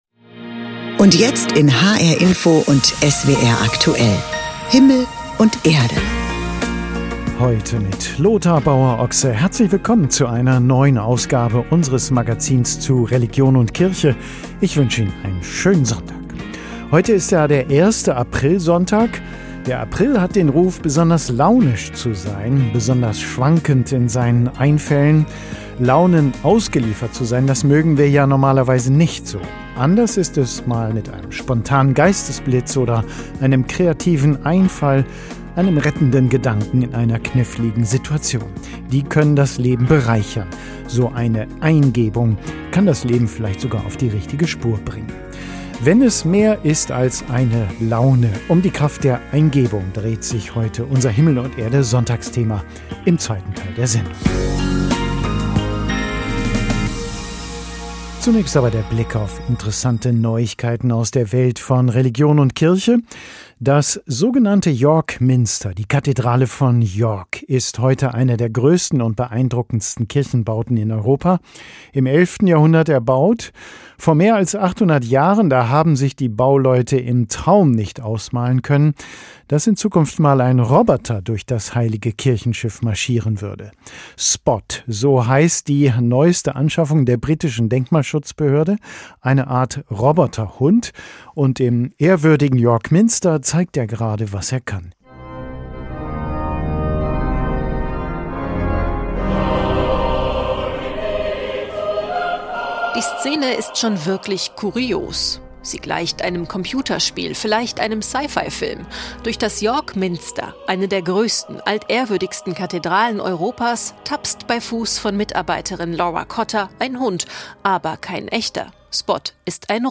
Ein Theologe erklärt uns, was für Eingebungen eigentlich die biblischen Propheten hatten.